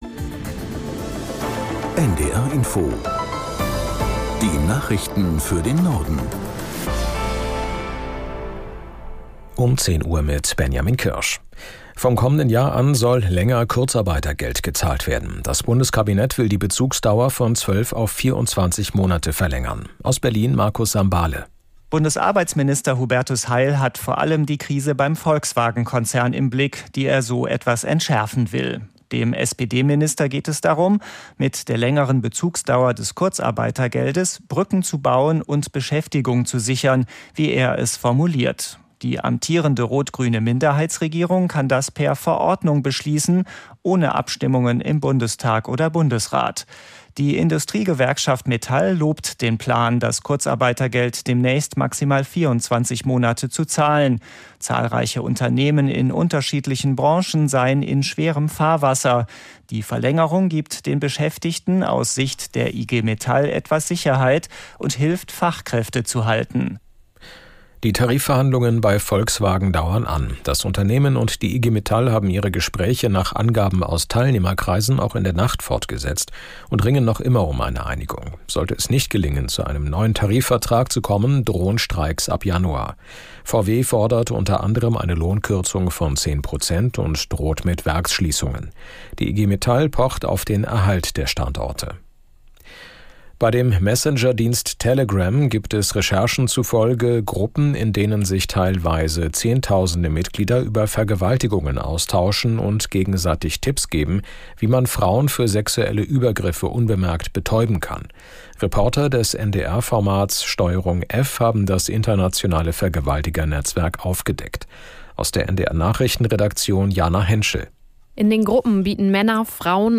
1 Nachrichten 4:52